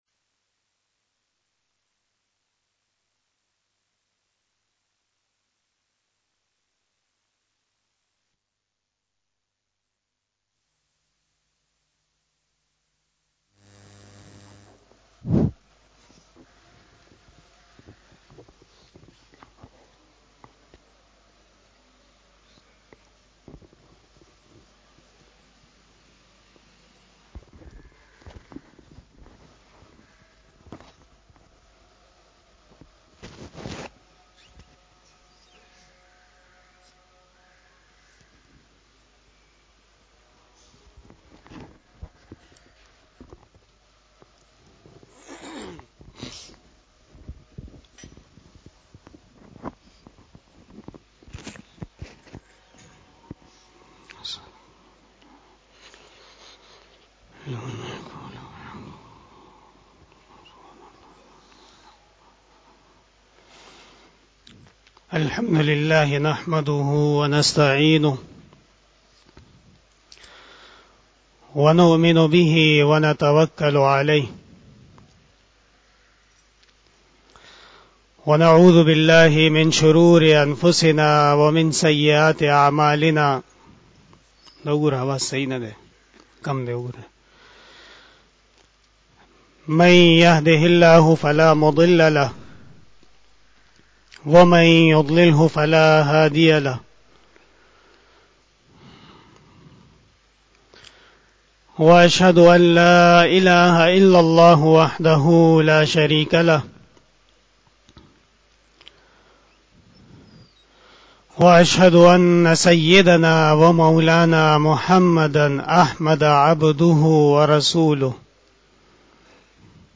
04 BAYAN E JUMA TUL MUBARAK 28 Januay 2022 (24 Jumadi ul Sani 1443H)
Khitab-e-Jummah 2022